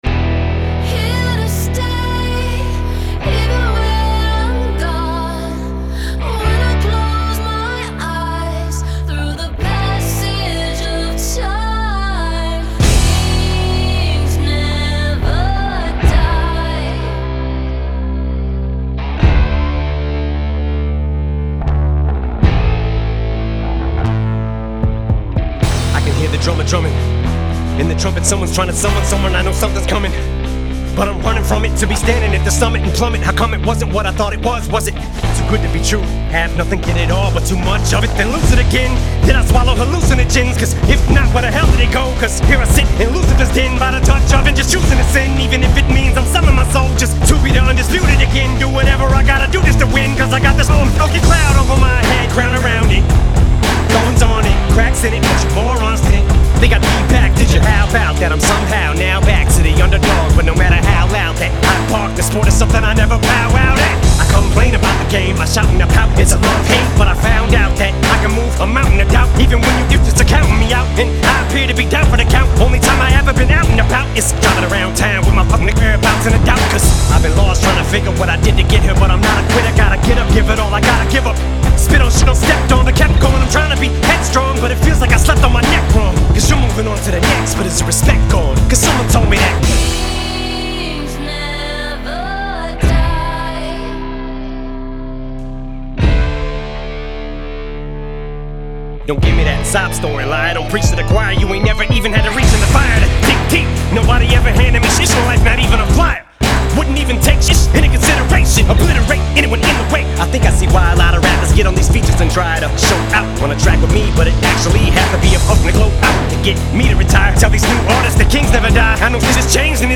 Genre : Rap, Hip-Hop